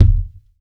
Object Deep Thud
impact_deep_thud_bounce_03.wav